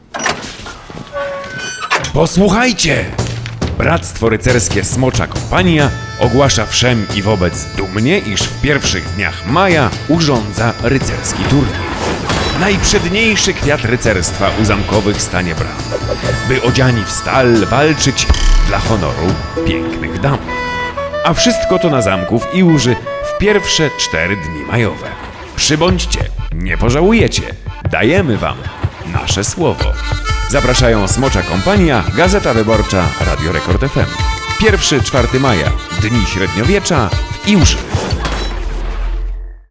Spot radiowy